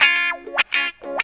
GTR 92 C#MAJ.wav